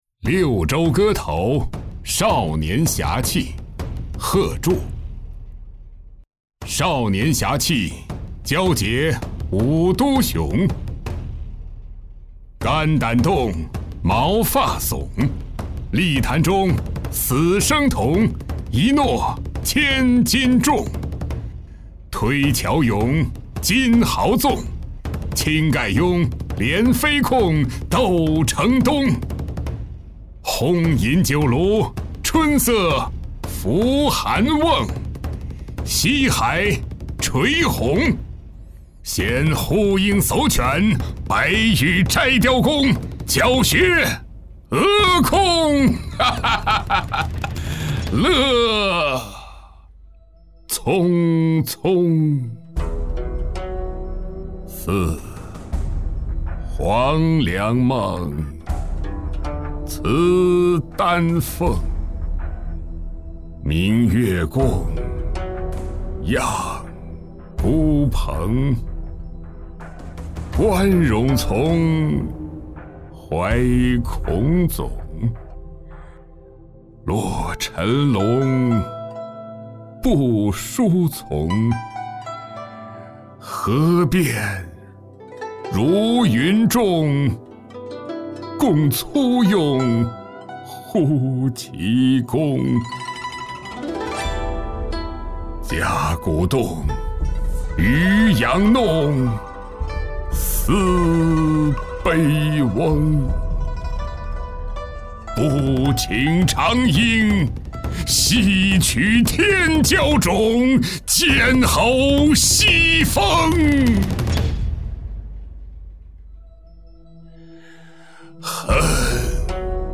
贺铸-六州歌头·少年侠气-配乐版.mp3